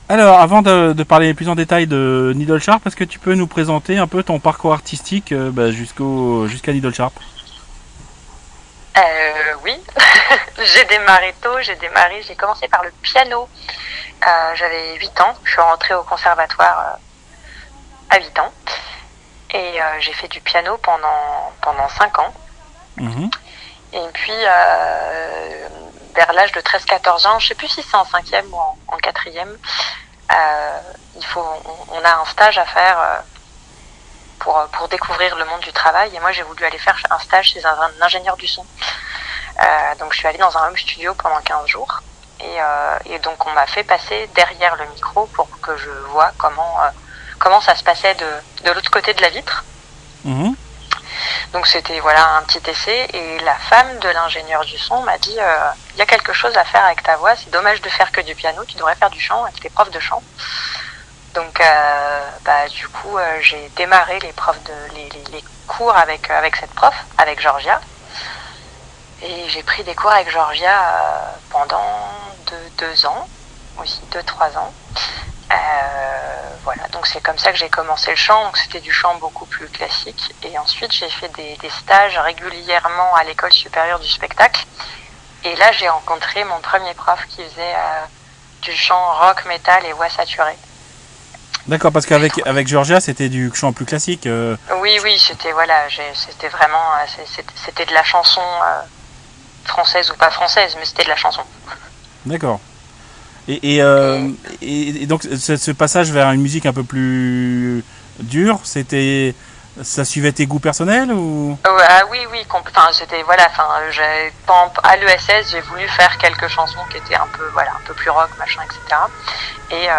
NEEDLE SHARP (interview